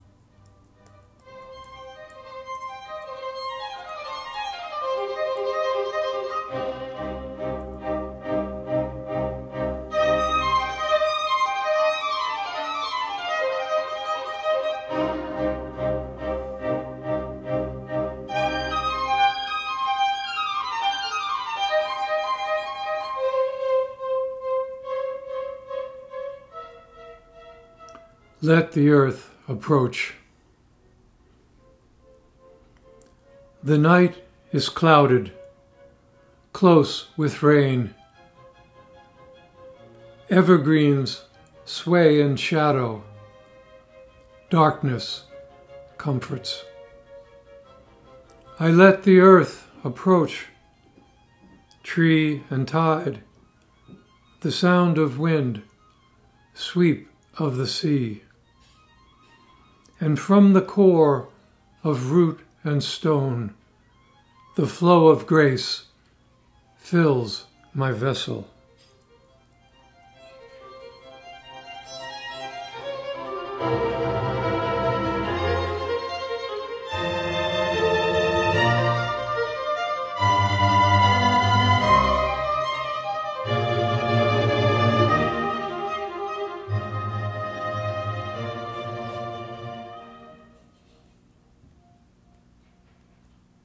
Reading of “Let the Earth Approach” with music by Vivaldi.